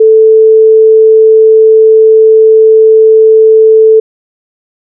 TestSine_Float32.wav